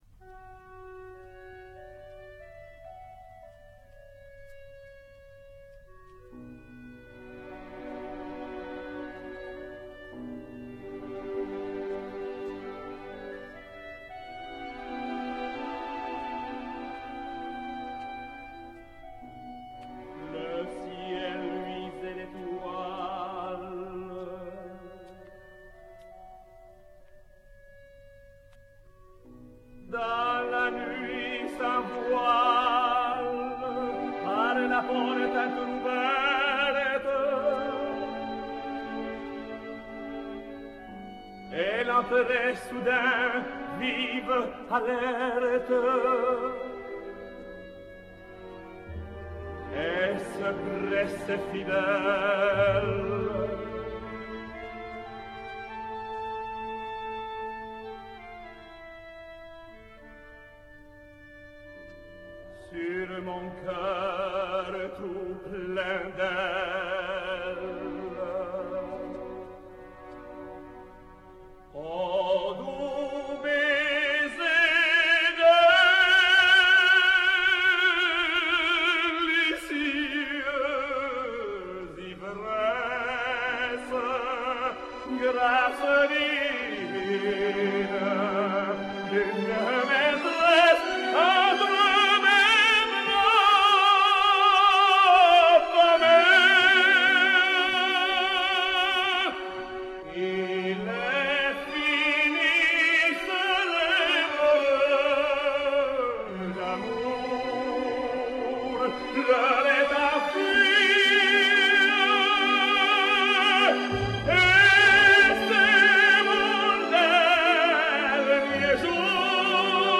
Luis Mariano sings Tosca:
His "Le ciel luisait d'étoiles" wasn't originally published, probably because of the sound quality – early stereo experiments...